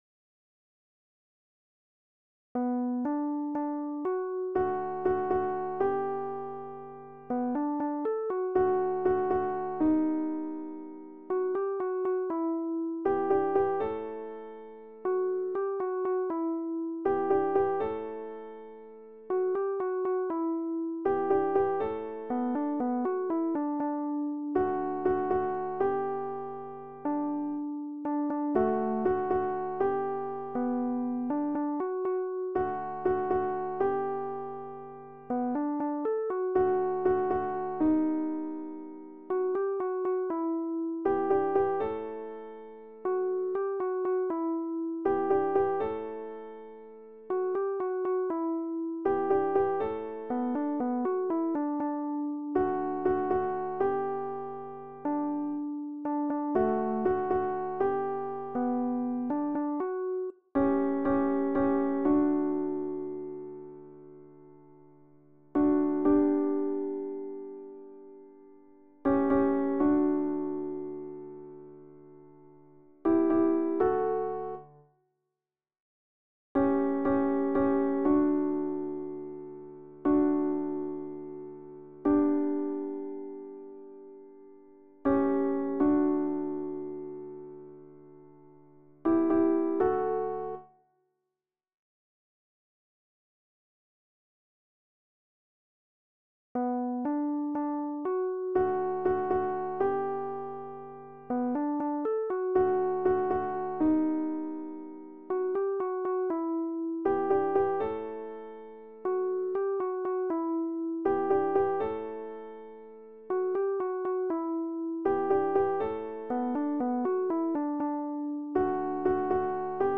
Oh happy day - Alto - Chorale Concordia 1850 Saverne
Oh-happy-day-Alto.mp3